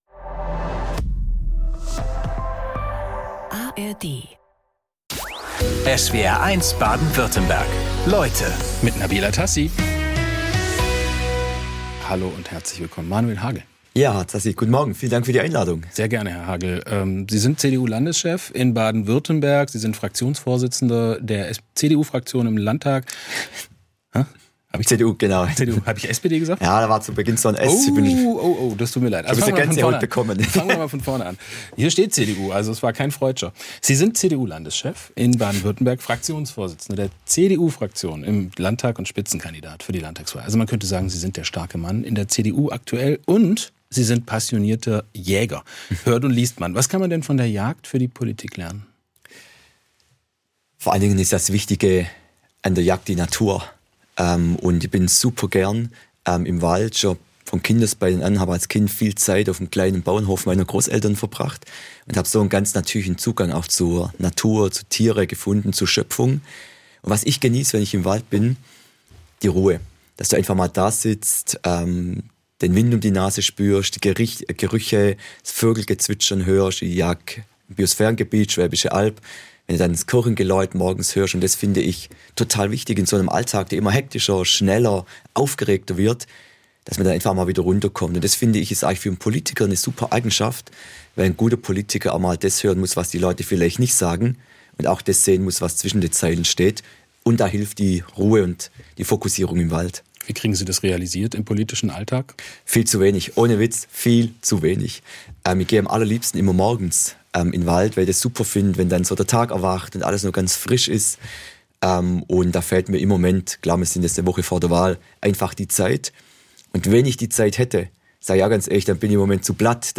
Vor der Landtagswahl 2026 sind Spitzenpolitiker:innen aus Baden-Württemberg zu Gast in SWR1 Leute, unter anderem Manuel Hagel von der CDU. Wir haben mit ihm über die Themen Wirtschaft, Migration, Bildung, Mobilität & Verkehr, Umwelt- und Klimaschutz gesprochen.